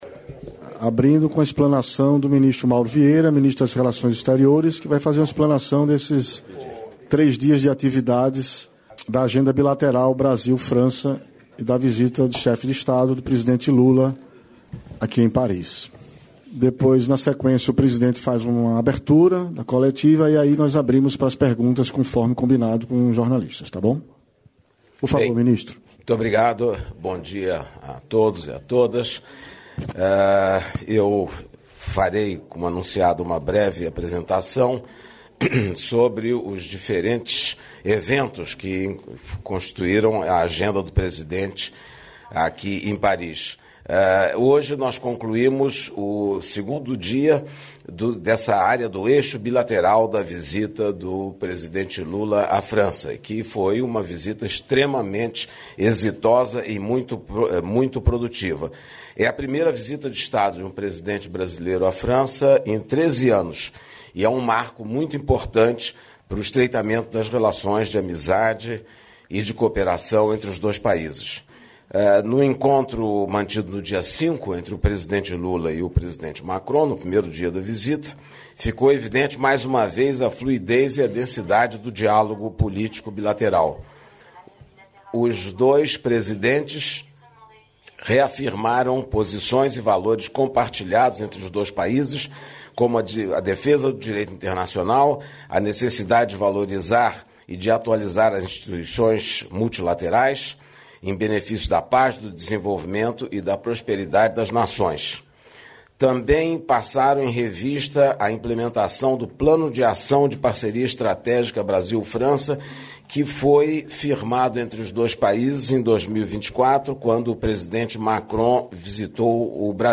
Íntegra da declaração à imprensa do secretário-executivo do Ministério da Fazenda, Dario Durigan, nesta terça-feira (10), em Brasília, após reunião-almoço com a Frente Parlamentar do Empreendedorismo (FPE).